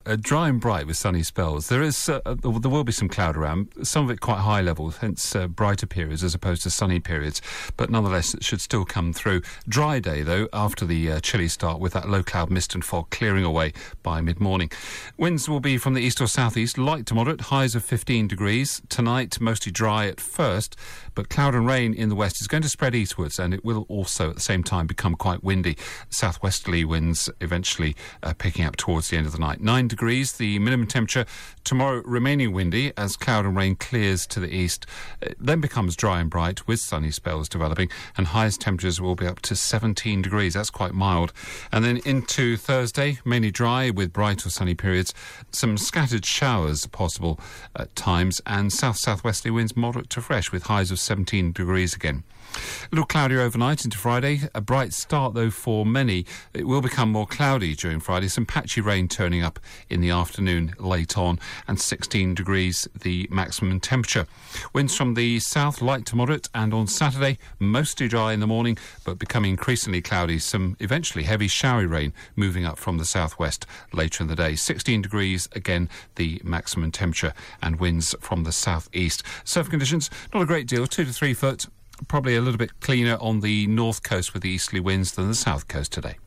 5 day forecast for Devon from 8.35AM on 15 October